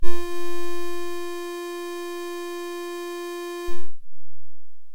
描述：使用Arturia Minibrute合成器的简单设置播放F4音符。这被记录为大号录音实验的参考声音。
Tag: F4 合成器 注意